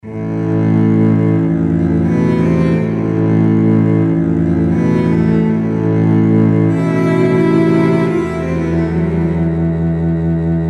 大提琴演奏3
描述：直接向前的最小技术风格的节拍集。
Tag: 140 bpm Techno Loops Drum Loops 2.31 MB wav Key : Unknown